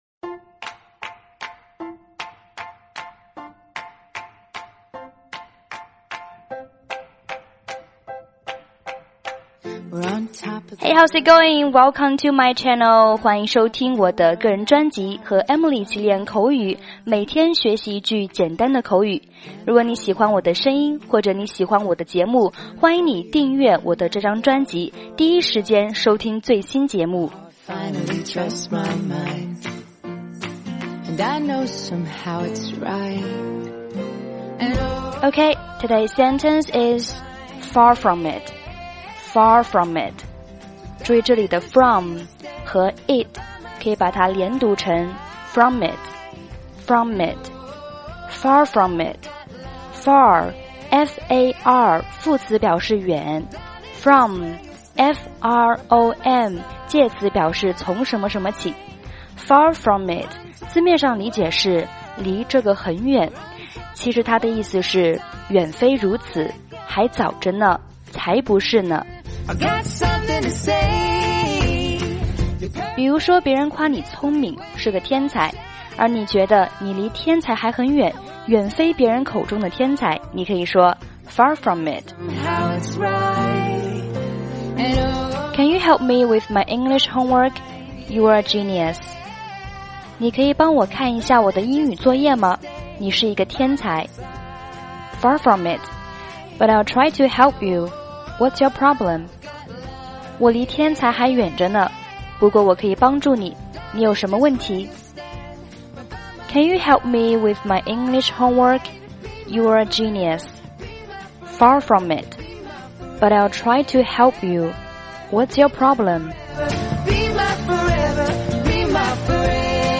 背景音乐：